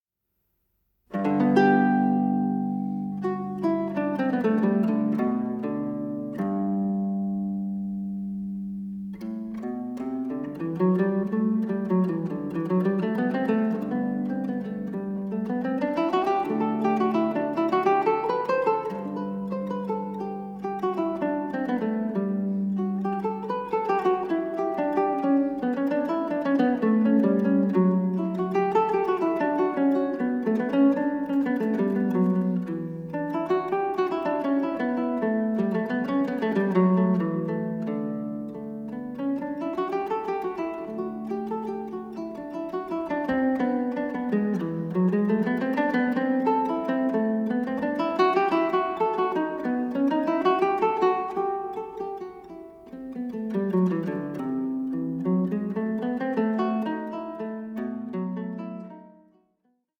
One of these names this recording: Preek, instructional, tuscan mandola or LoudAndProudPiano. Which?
tuscan mandola